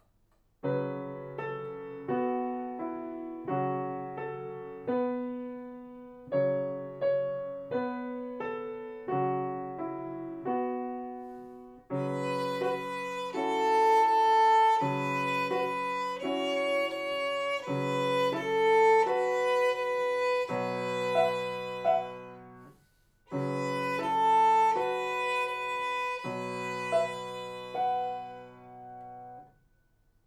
ご自宅での練習用に録音しました。